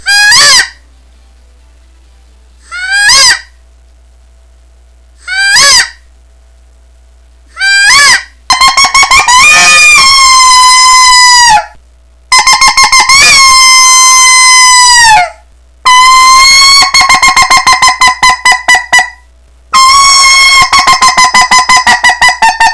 Listen to 23 seconds of peacock, coyote,  & pileated woodpecker calls
• Loud, Reed-Type Locator Call With Volume-Enhancing Horn, imitates the sounds of a peacock, and pileated woodpecker...all of which tortures toms into gobbling in response so you know where they are.
qbpeacockcoyotewood23.wav